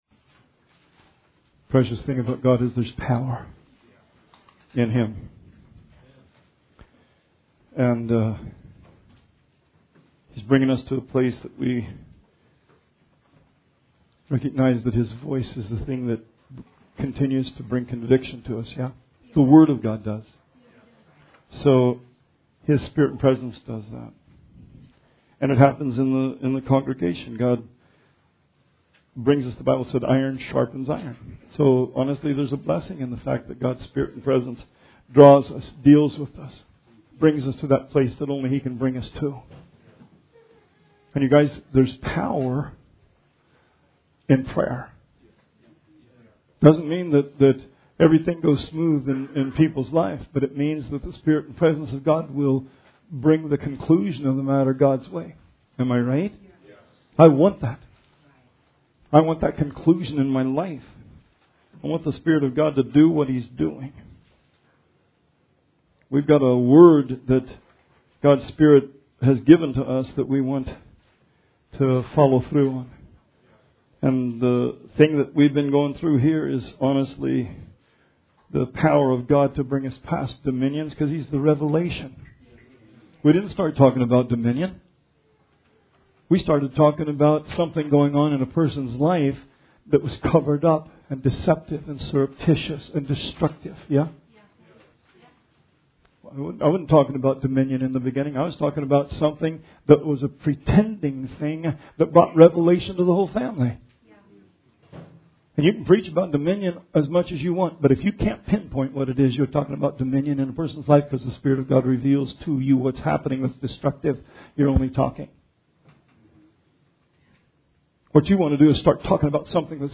Sermon 7/21/19